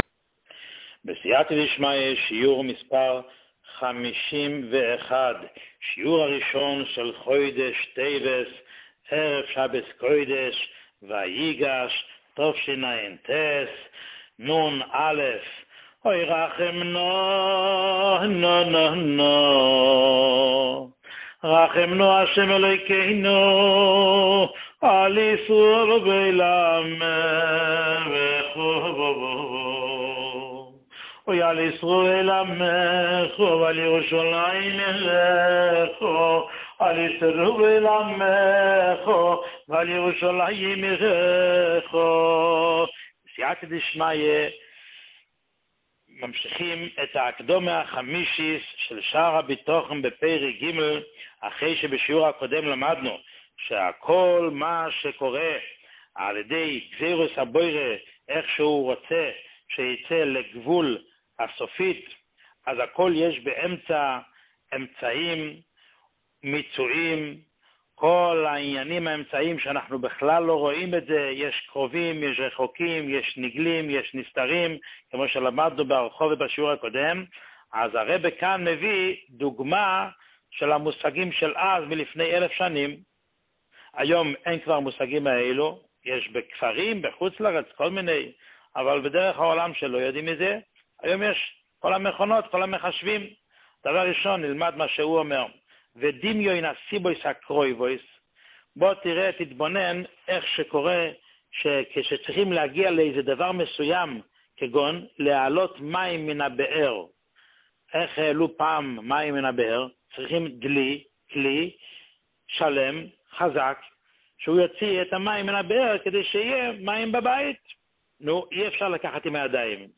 שיעור 51